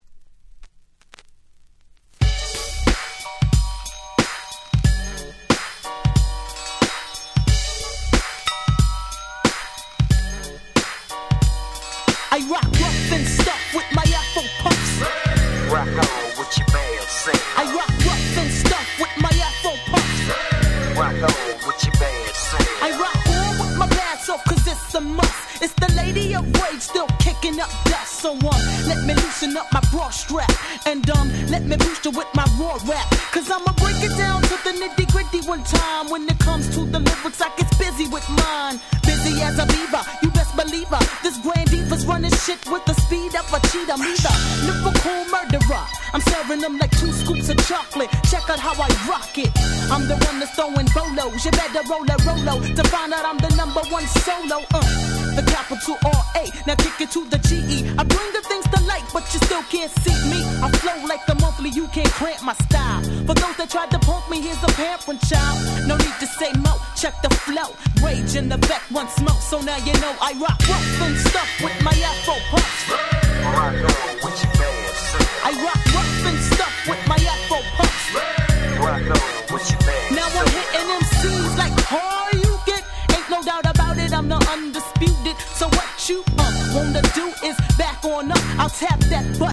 94' Smash Hit Hip Hop / G-Rap / Gangsta Rap !!